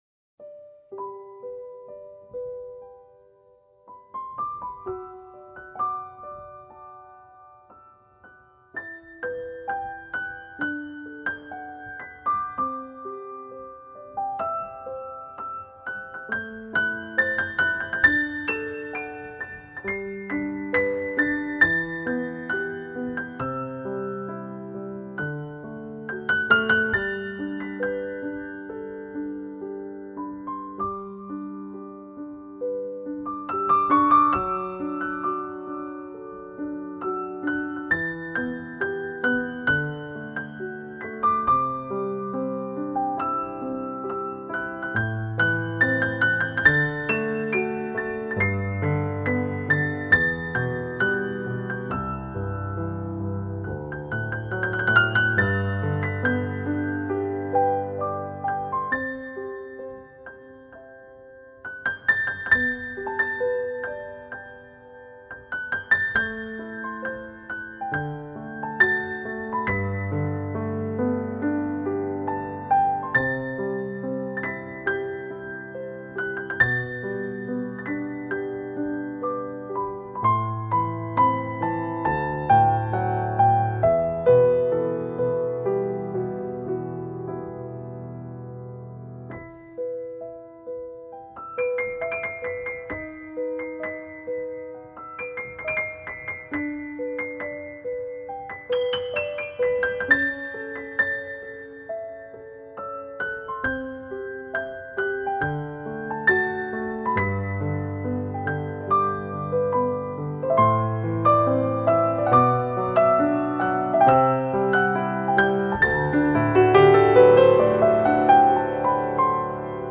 主要演奏器乐： 钢琴
乐风： 疗癒、新世纪、放鬆、流行
春夏之际，最满溢自然气息的钢琴甜美创作